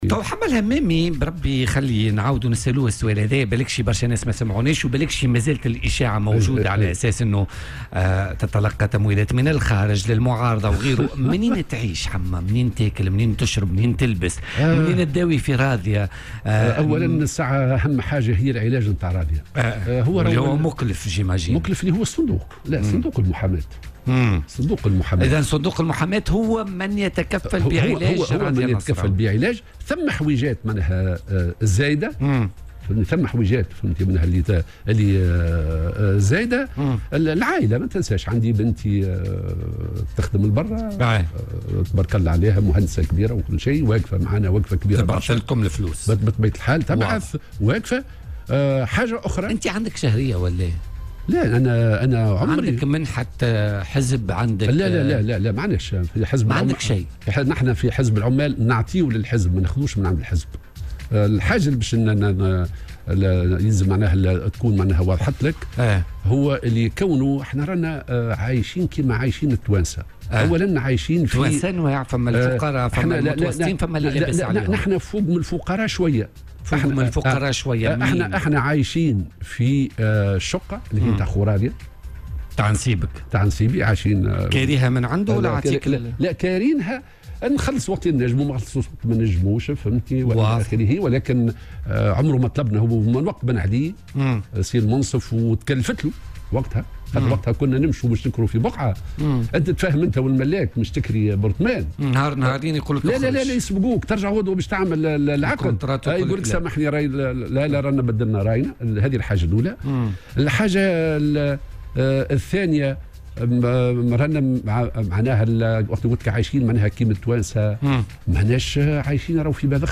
وأضاف في مداخلة له اليوم في برنامج "بوليتيكا" على "الجوهرة أف أم"، أنه يقيم في منزل على ملك شقيق زوجته، ويقتات من الأجر الشهري الذي ترسله ابنته التي تقيم في الخارج، في حين يتكفل صندوق المحاماة بمصاريف علاج زوجته.